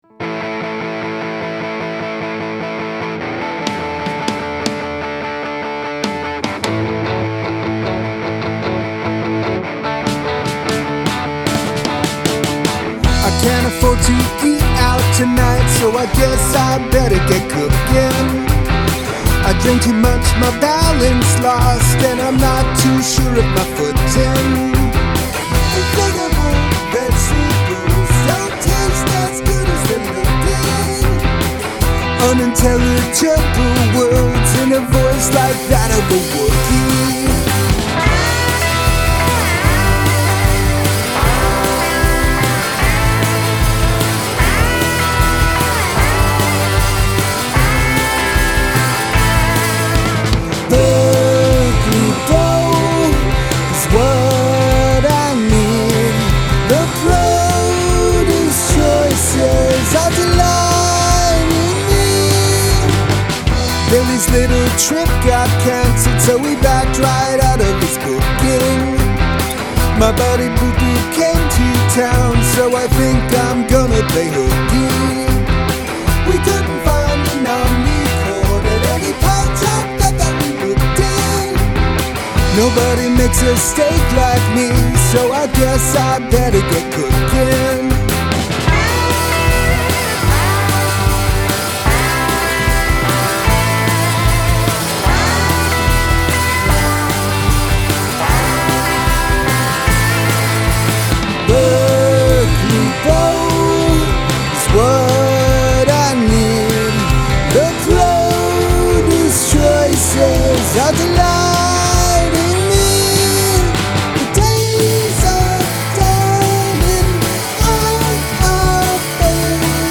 Guitar, bass, Vocals